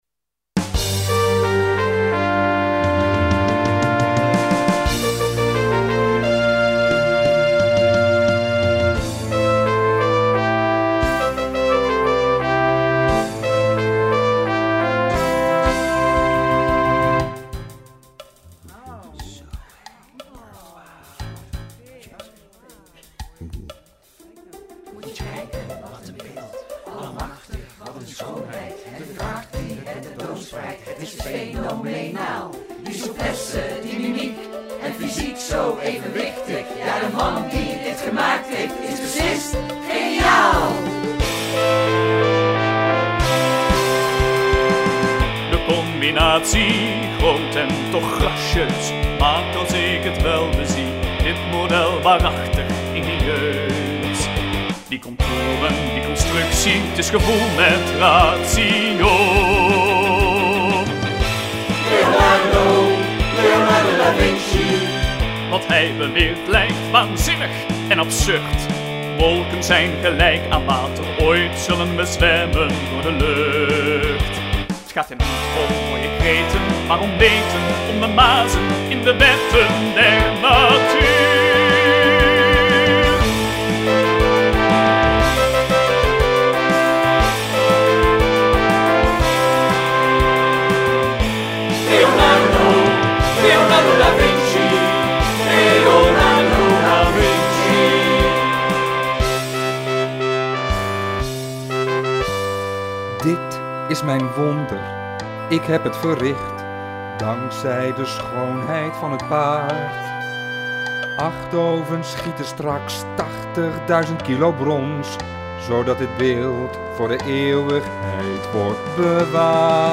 Uit “Da Vinci” (musical)
Koor: Perpleks